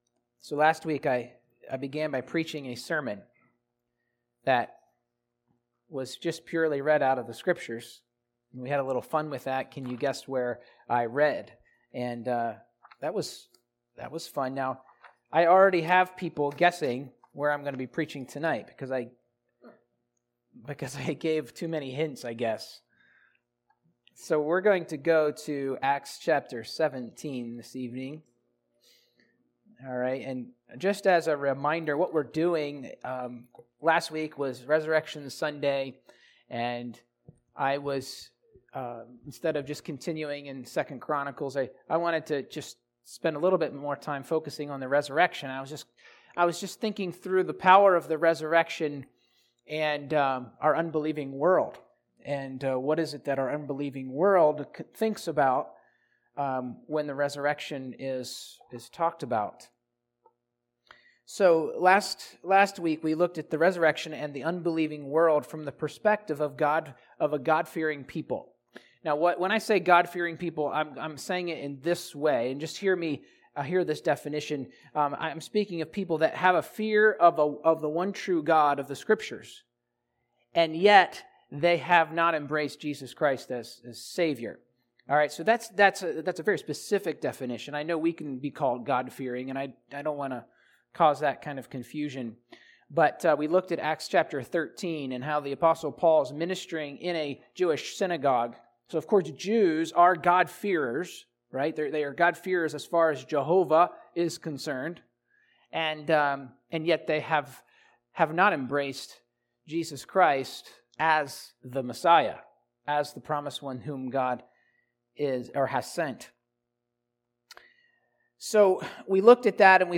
1 Peter 1:3-5 Service: Sunday Morning